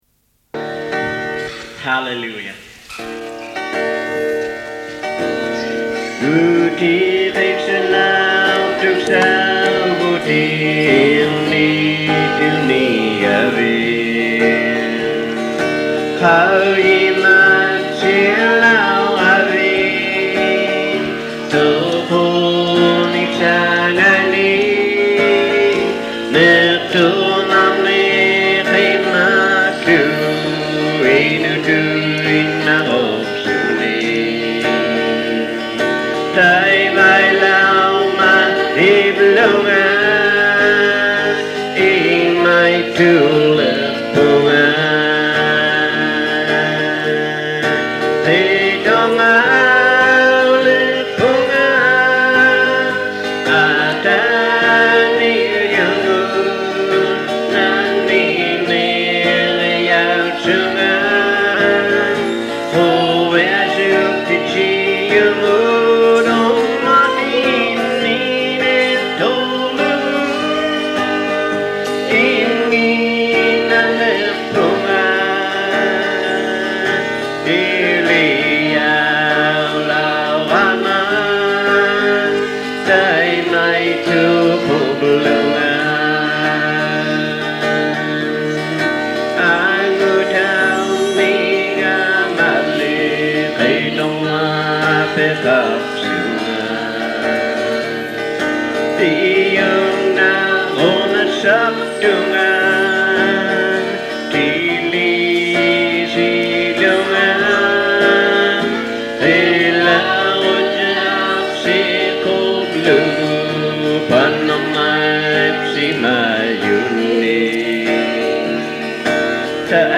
101 Gospel song:   03:42m
Inuit Gospel crusade song, with a country western beat.